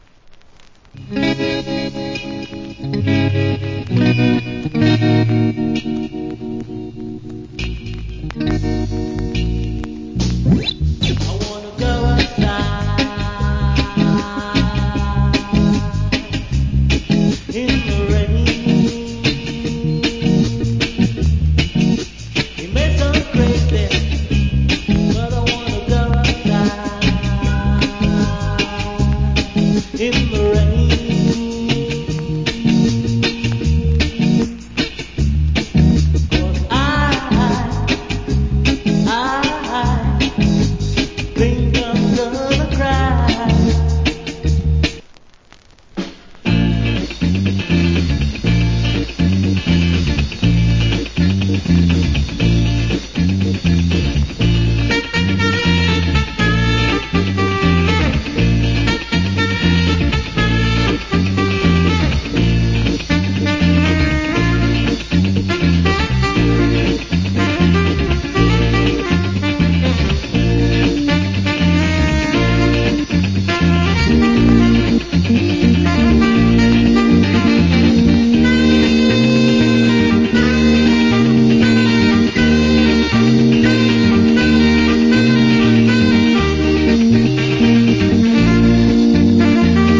Rare. Nice Reggae Vocal.